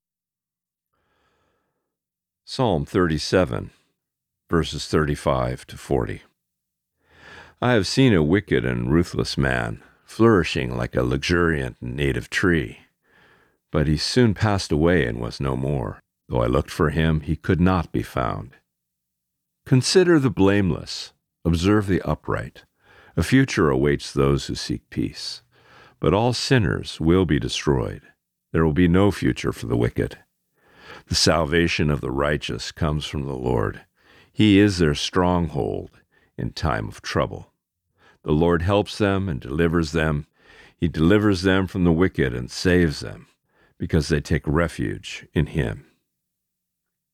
Today’s Reading: Psalm 37:35-40